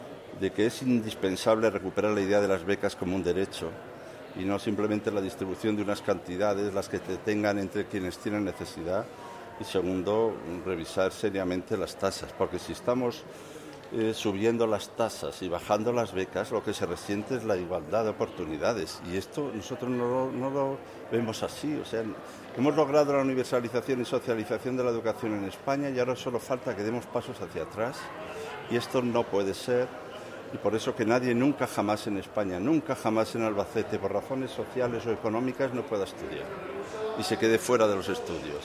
El ex ministro socialista de Educación, Ángel Gabilondo, ha expuesto en la Filmoteca de Albacete las propuestas socialistas en materia de Educación.
Cortes de audio de la rueda de prensa